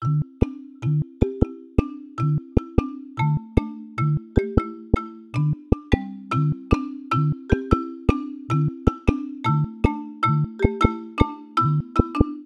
alarms